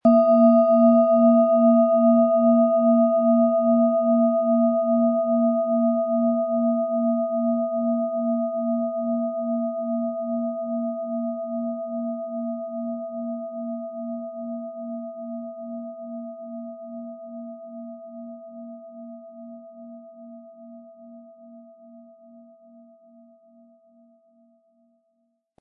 Hopi Herzton
• Mittlerer Ton: Mond
Durch die überlieferte Fertigung hat sie dafür diesen besonderen Spirit und eine Klangschwingung, die unser Innerstes berührt.
Den passenden Klöppel erhalten Sie umsonst mitgeliefert, er lässt die Schale voll und wohltuend klingen.
MaterialBronze